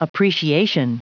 Prononciation du mot appreciation en anglais (fichier audio)
Prononciation du mot : appreciation